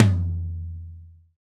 TOM TOM 89.wav